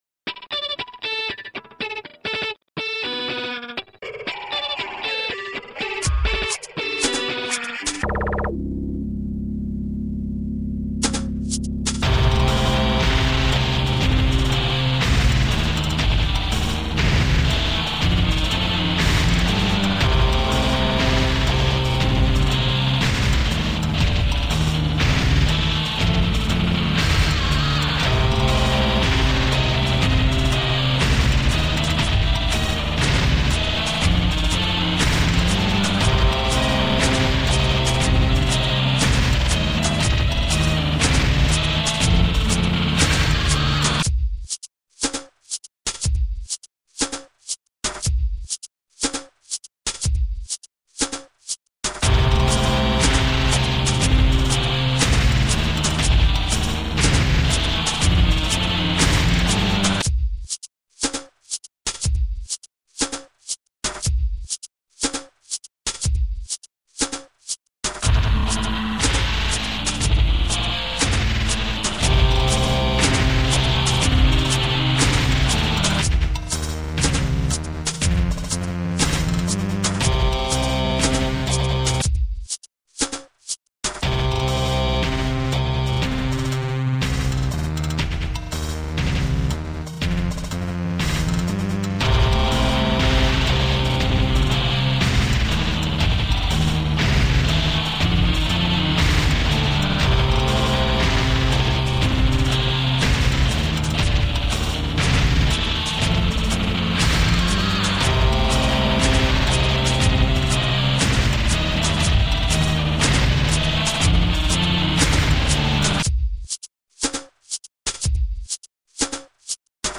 grinding loop salad